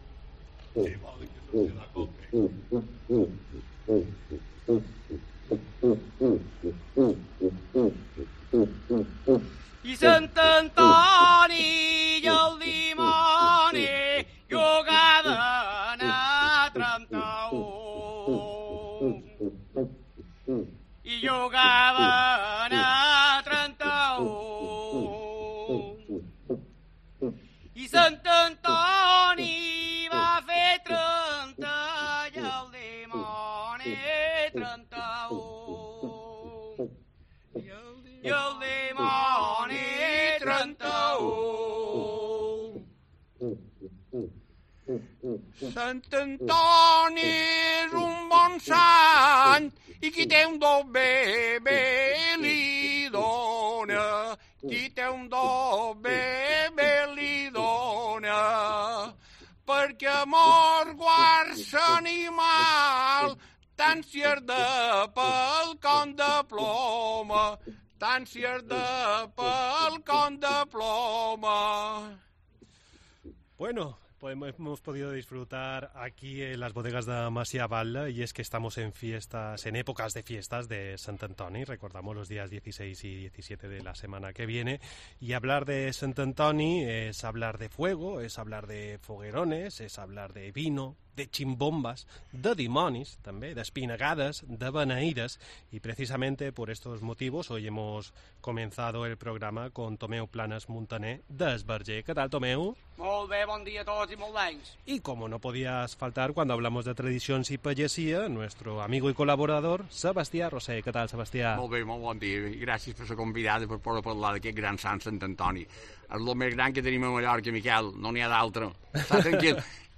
un glosador que toca la ximbomba desde que era niño